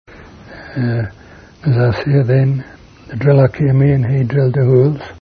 interview: The Drillers